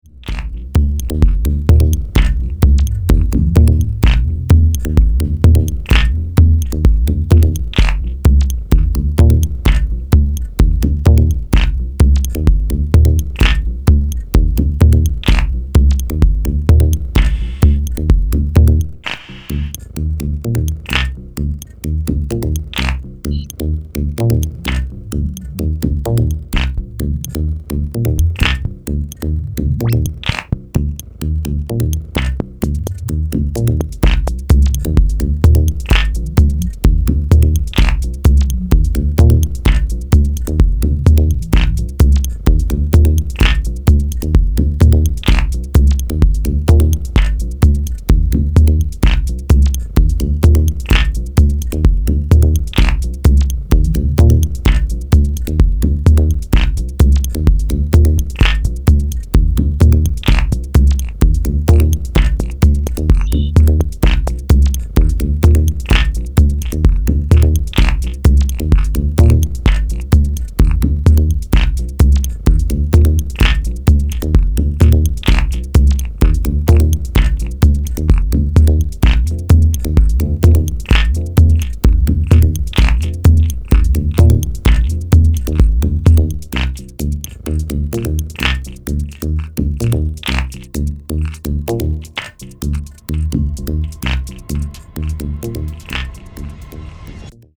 妖艶に蠢くアヴァンギャルドな音像、あくまでもファンキーでしなやかなハウスビート。